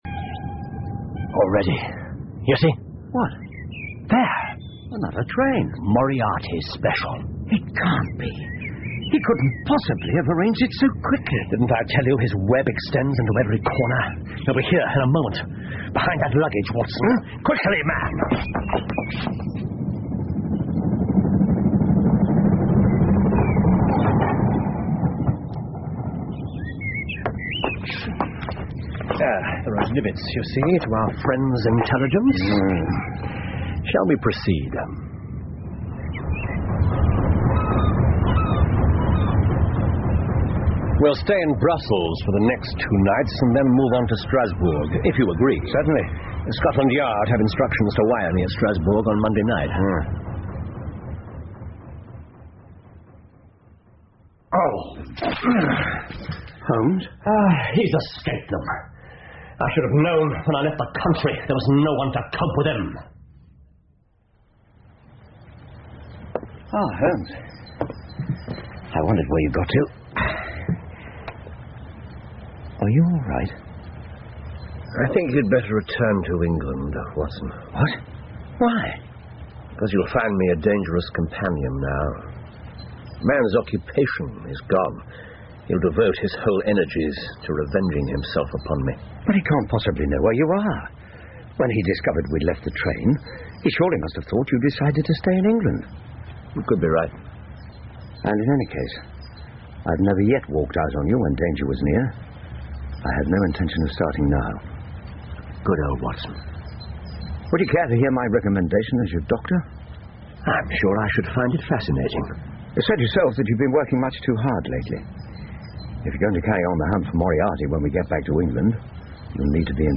福尔摩斯广播剧 The Final Problem 6 听力文件下载—在线英语听力室